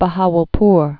(bə-häwəl-pr, -häwəl-pr)